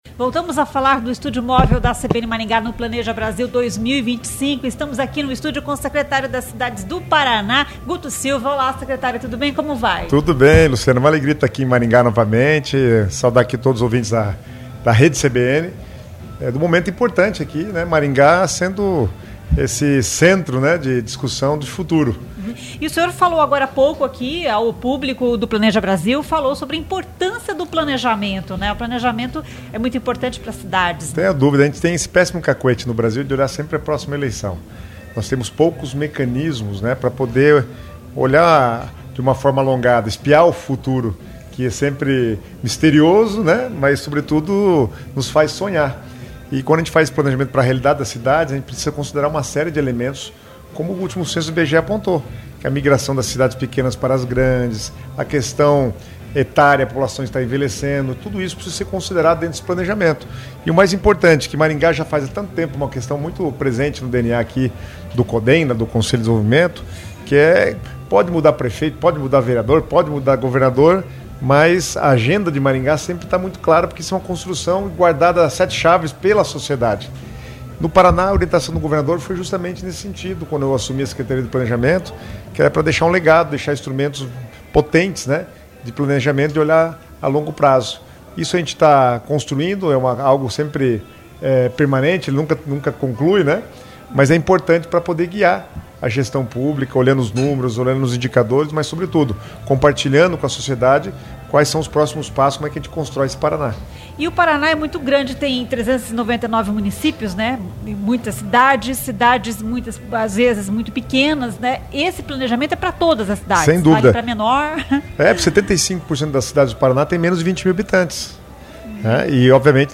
Estúdio Móvel CBN
A entrevista foi realizada no estúdio móvel CBN instalado no local do evento.